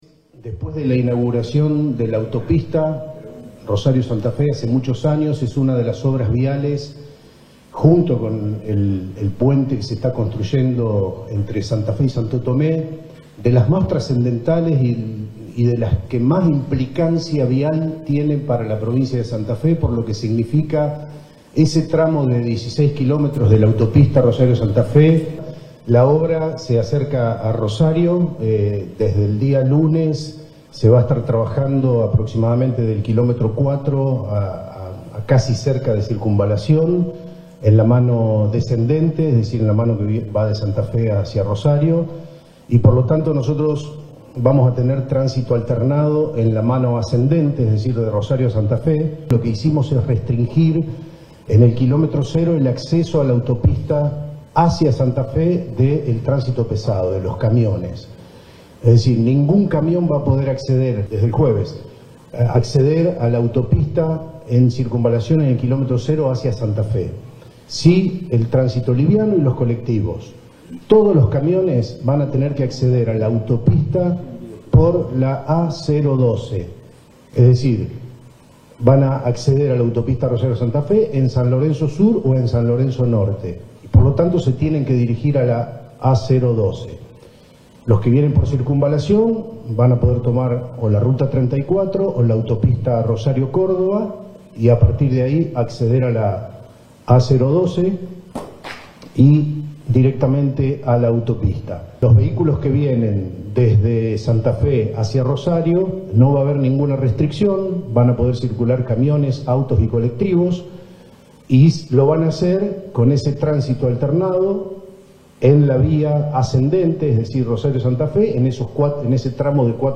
Así lo anunciaron el director provincial de Vialidad, Pablo Seghezzo, y los secretarios de la Agencia Provincial de Seguridad Vial, Carlos Torres, y de Protección Civil y Gestión de Riesgos, Marcos Escajadillo, durante una conferencia de prensa realizada en la Sede de Gobierno en Rosario.
Declaraciones de Torres, Seghezzo y Escajadillo